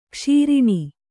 ♪ kṣīriṇi